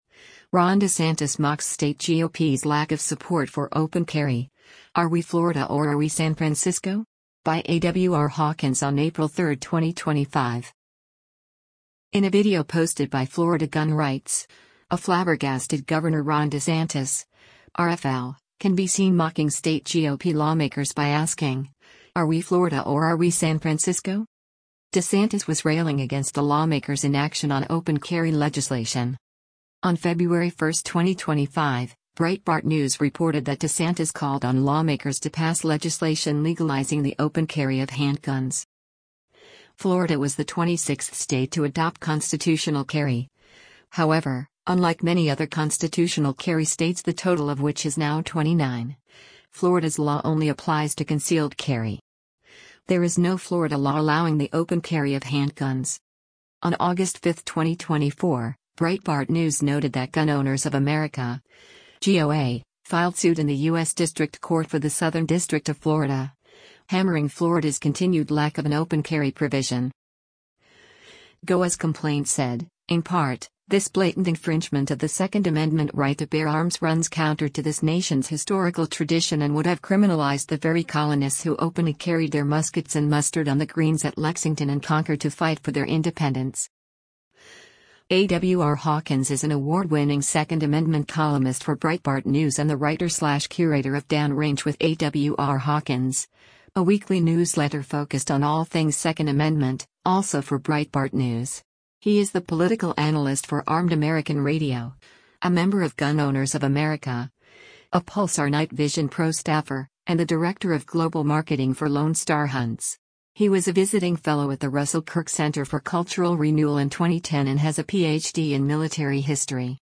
In a video posted by Florida Gun Rights, a flabbergasted Gov. Ron DeSantis (R-FL) can be seen mocking state GOP lawmakers by asking, “Are we Florida or are we San Francisco?”